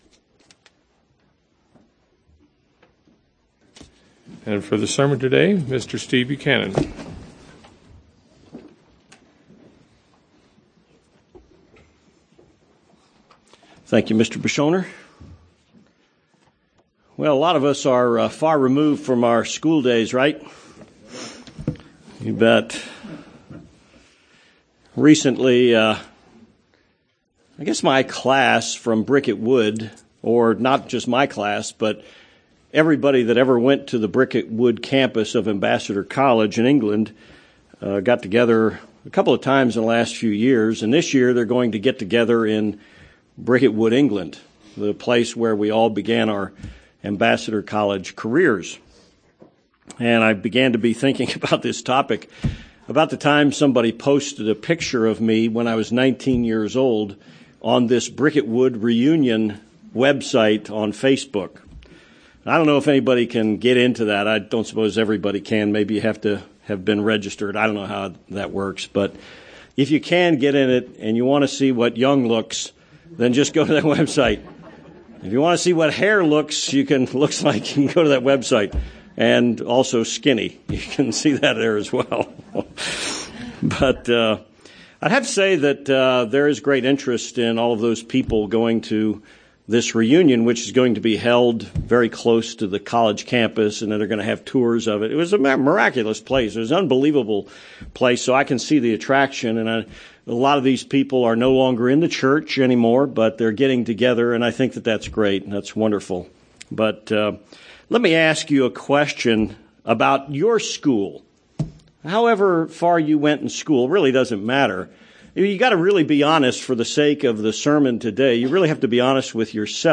Given in El Paso, TX
UCG Sermon Studying the bible?